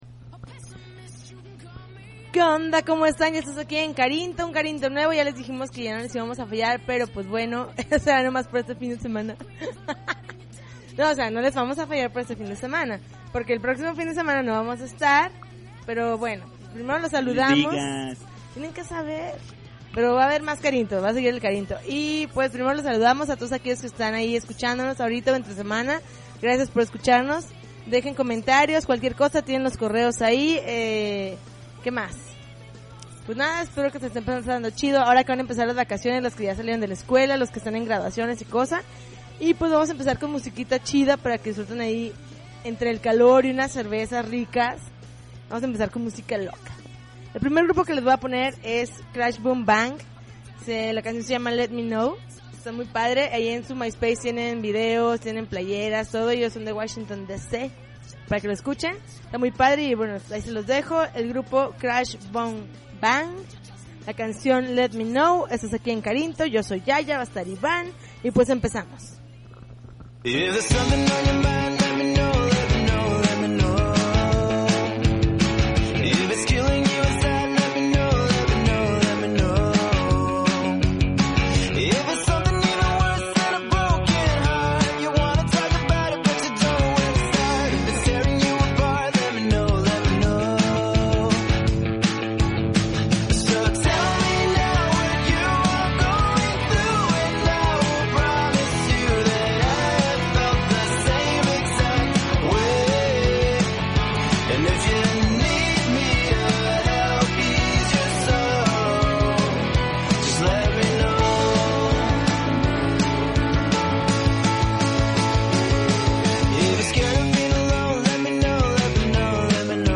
June 28, 2009Podcast, Punk Rock Alternativo